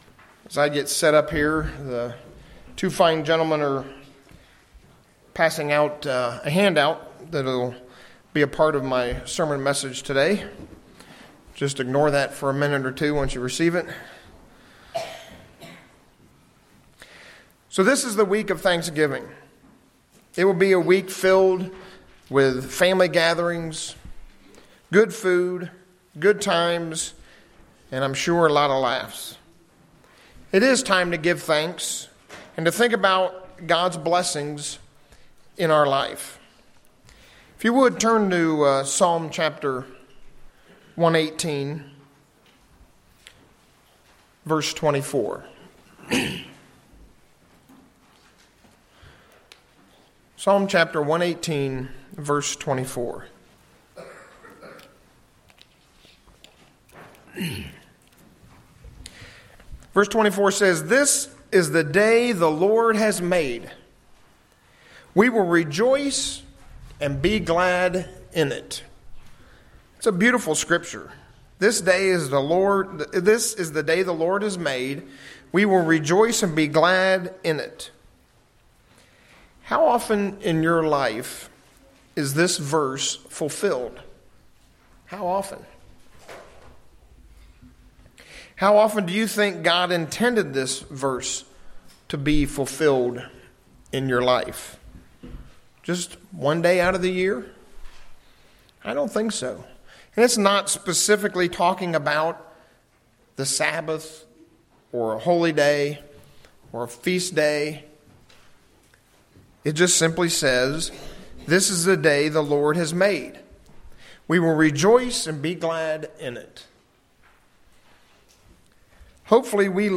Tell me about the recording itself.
Given in Ft. Wayne, IN